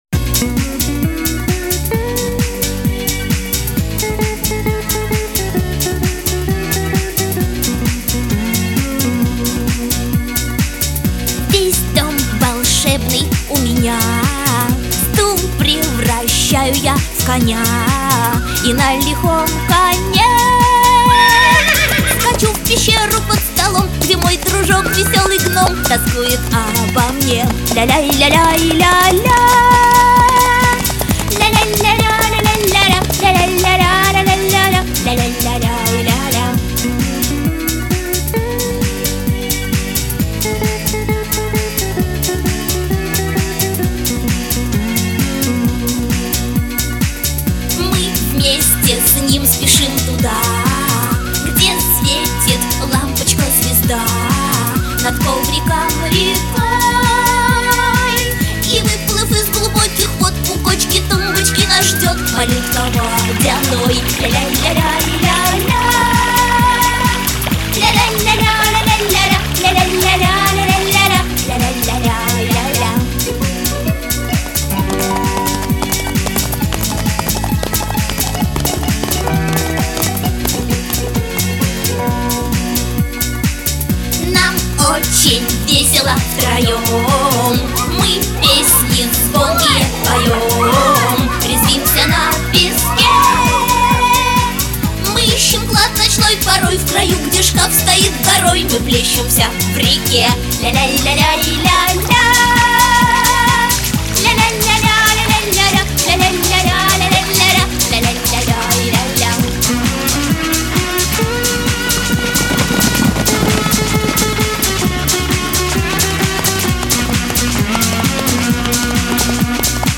• Качество: Хорошее
• Жанр: Детские песни